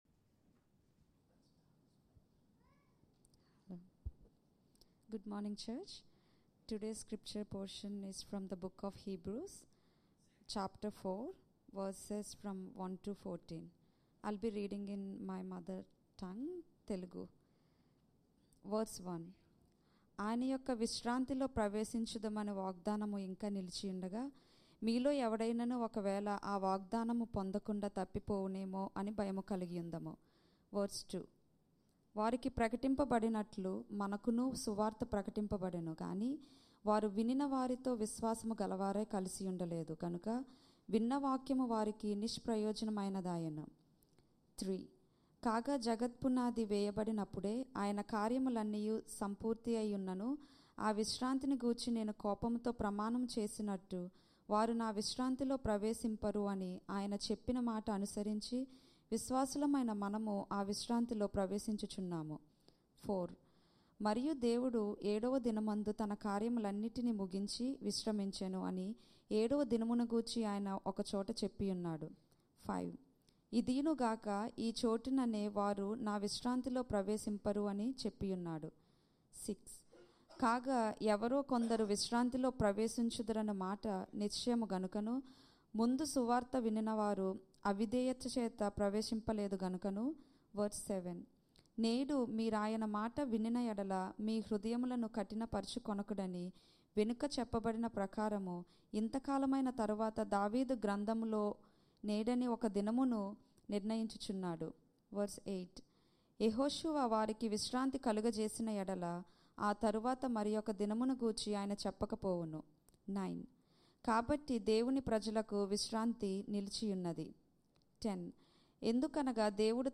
Sermons | Church At The Gabba